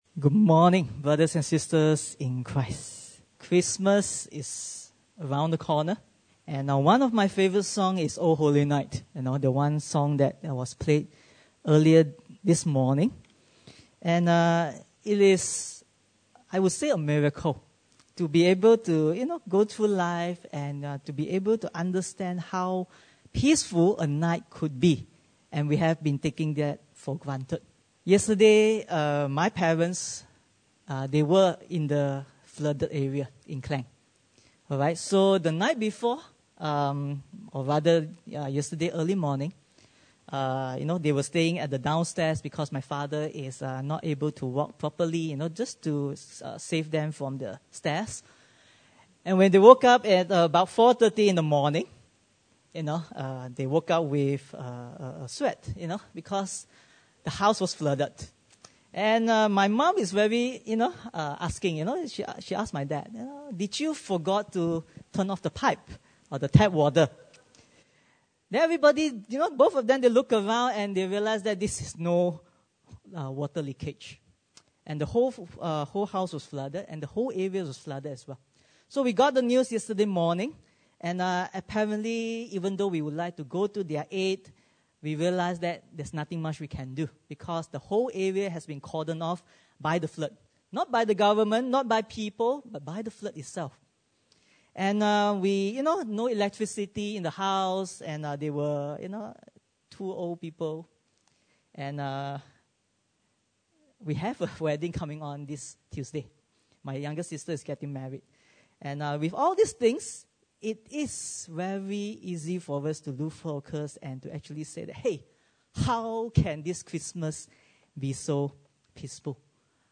Passage: 1 Peter 1:6-12 Service Type: Sunday Service